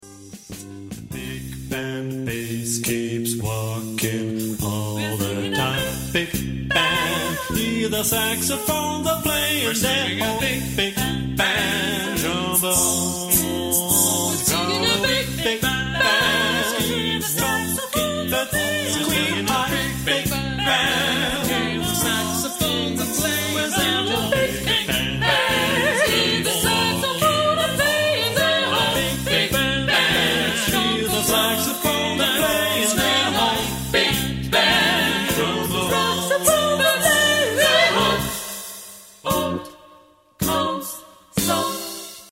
Género/Estilo/Forma: Profano ; Canon ; Swing ; Jazz
Carácter de la pieza : swing
Tonalidad : do mayor